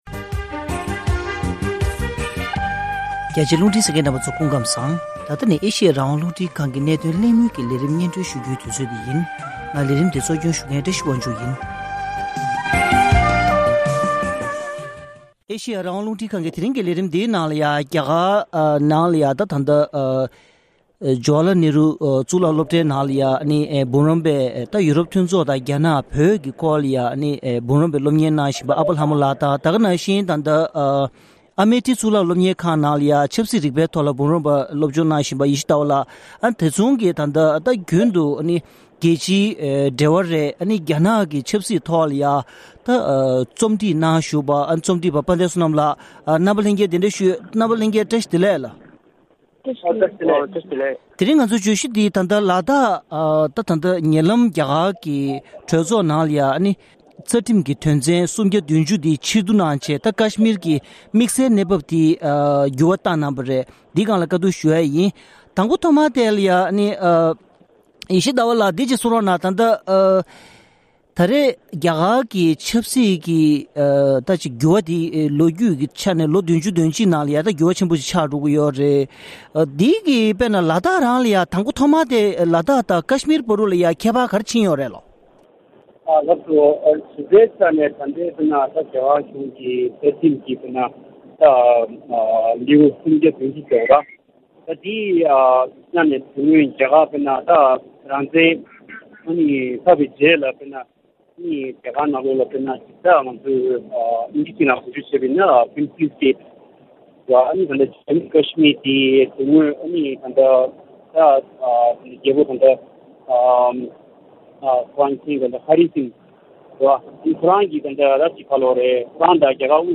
༄༅༎ཐེངས་འདིའི་གནད་དོན་གླེང་མོལ་གྱི་ལས་རིམ་ནང་།